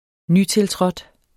Udtale [ -telˌtʁʌd ]